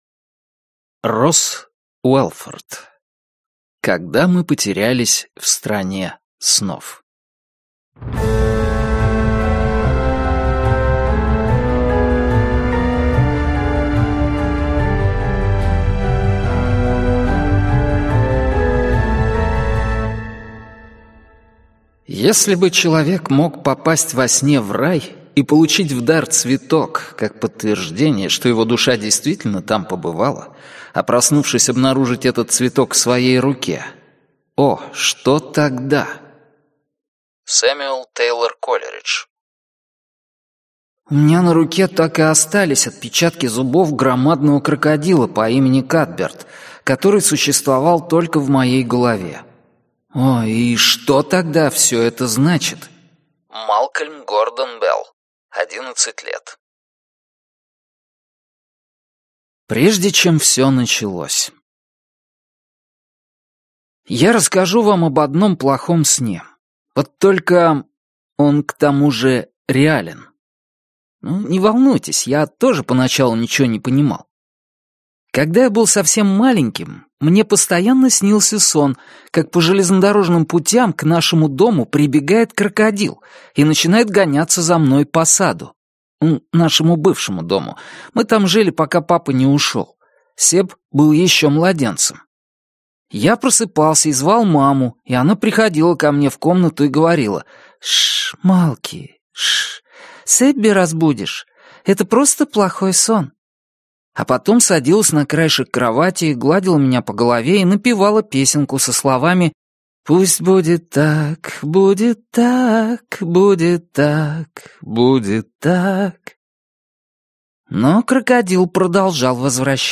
Аудиокнига Когда мы потерялись в Стране снов | Библиотека аудиокниг